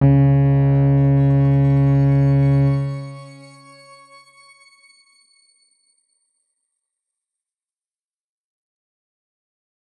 X_Grain-C#2-mf.wav